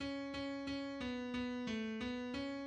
key Bm
transposed -5 from original Em